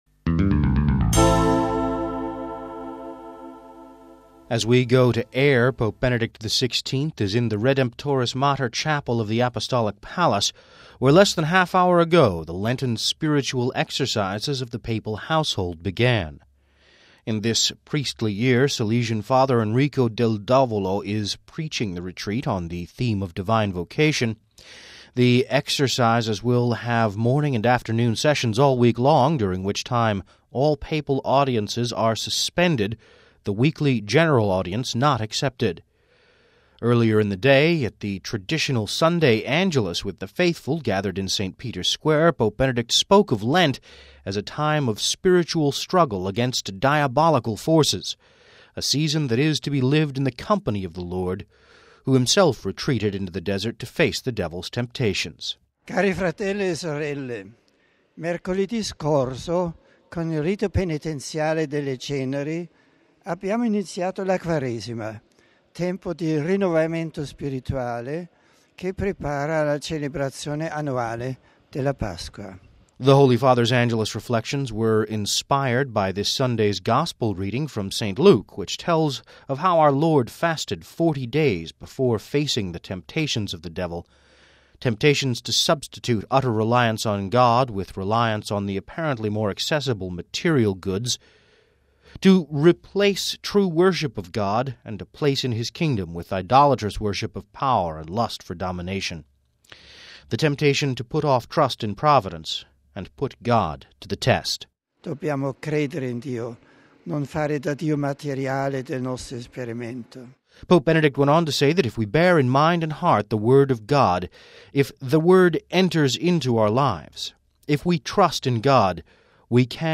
Home Archivio 2010-02-21 18:34:28 Pope Benedict XVI Begins Lenten Retreat (21 Feb 10 - RV) Pope Benedict XVI began his Lenten retreat Sunday evening. We have this report...